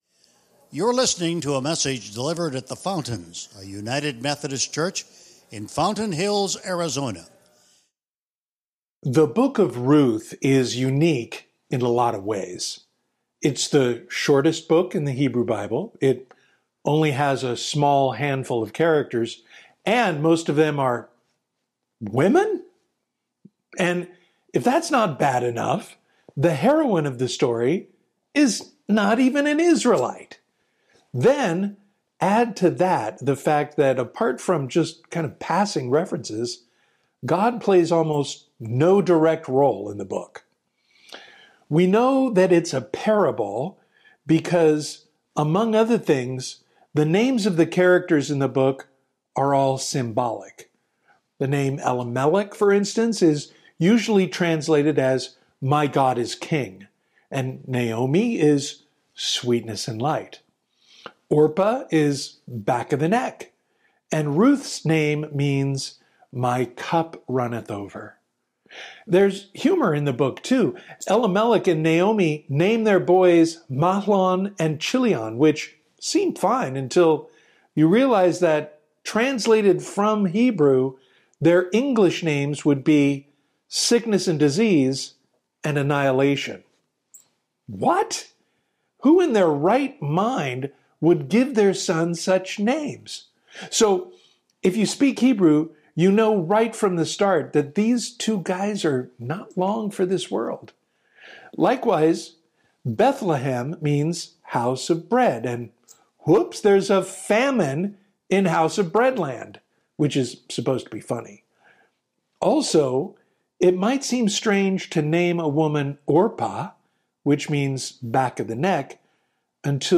Sermons | The Fountains, a United Methodist Church